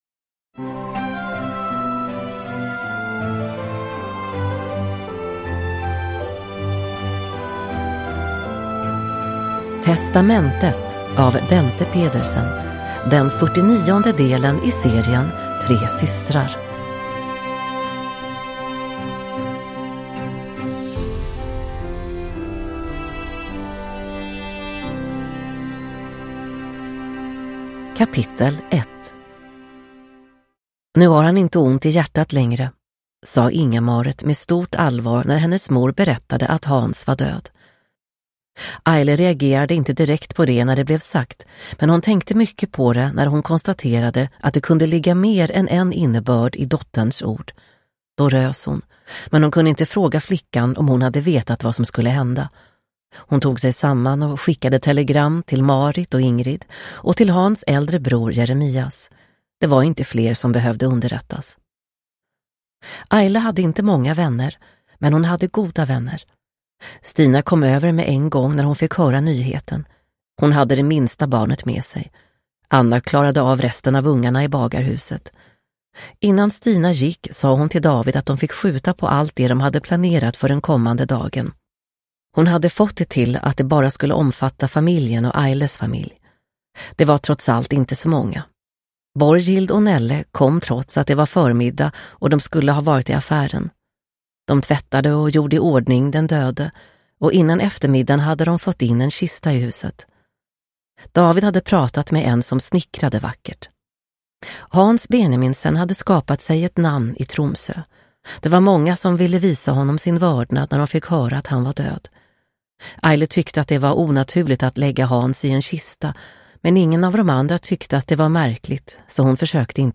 Testamentet – Ljudbok – Laddas ner